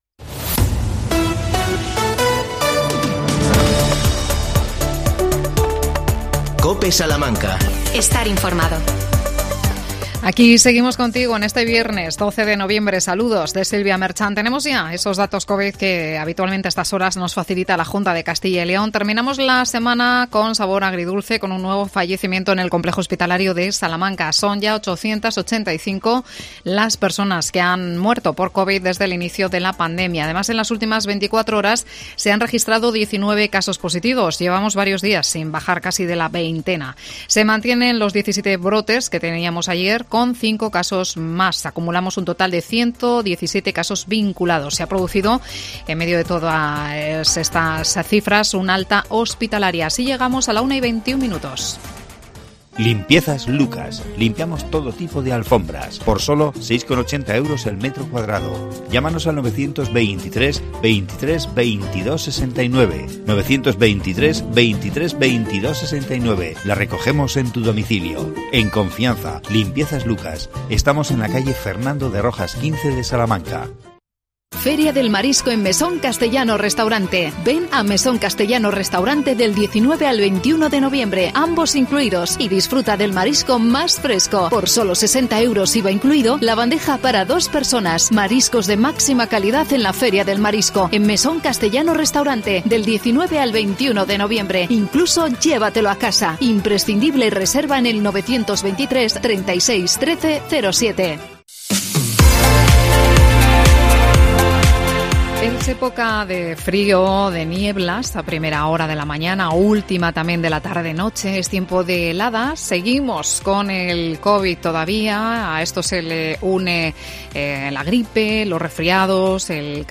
AUDIO: Entrevistamos